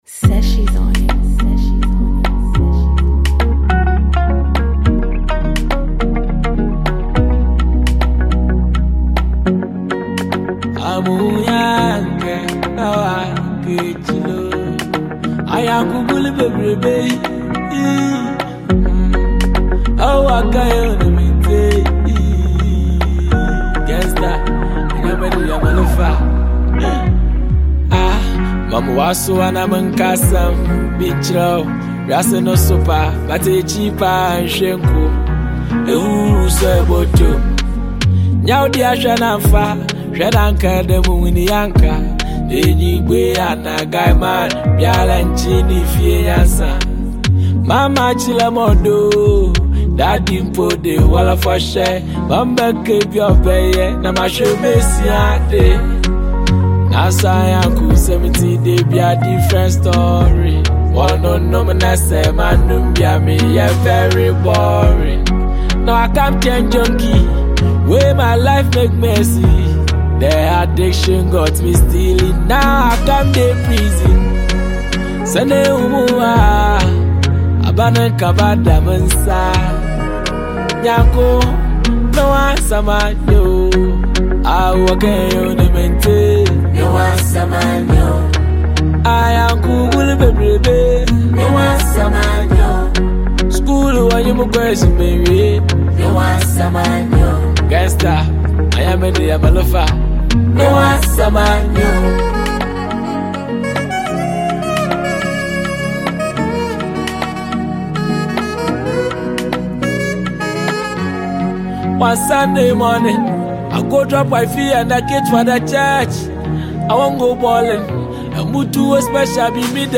Known for his rich harmonies and lyrical brilliance
Afrobeats